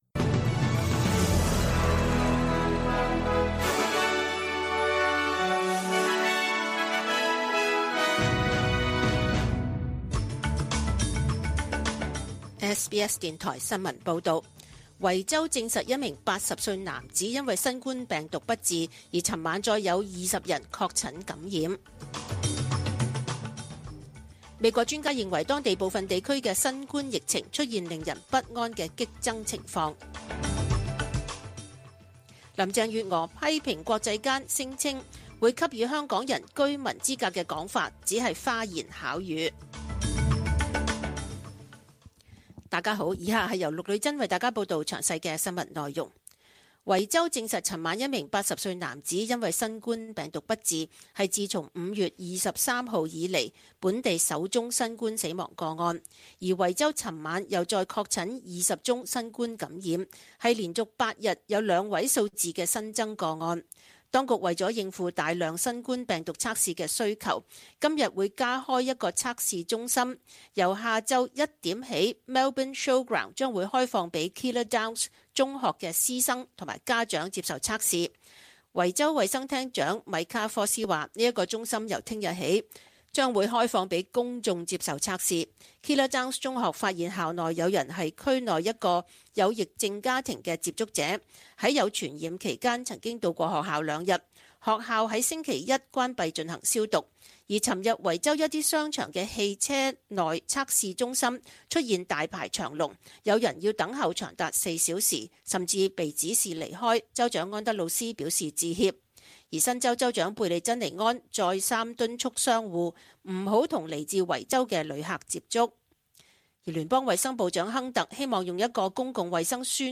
请收听本台为大家准备的详尽早晨新闻。
SBS 廣東話節目中文新聞 Source: SBS Cantonese